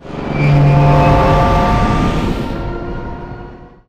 boost_2.wav